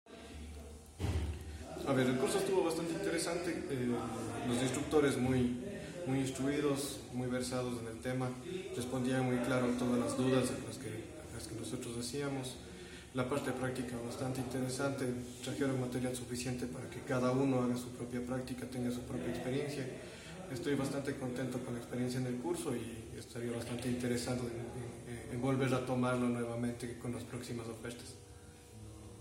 Testimonio:
Participante – Trabajador